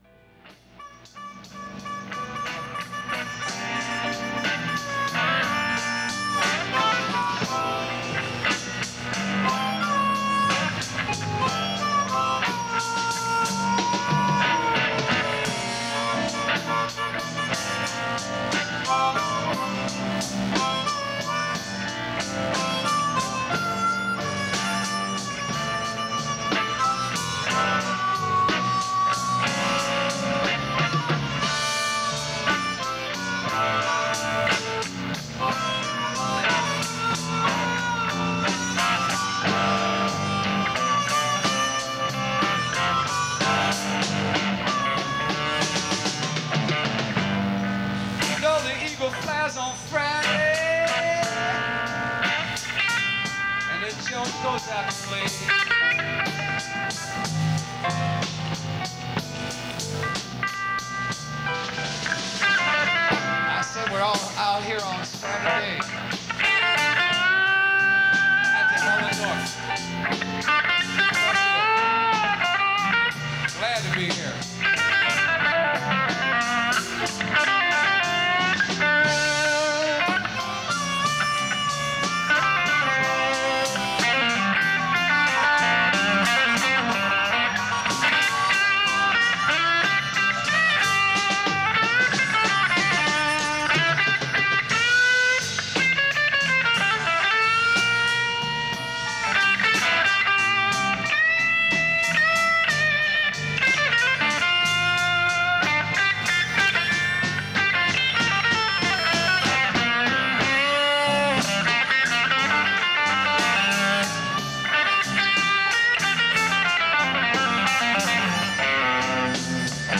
Category Rock/Pop
Studio/Live Live